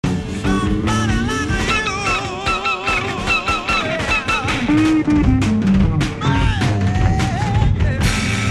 ライブ版タッドロロンMP3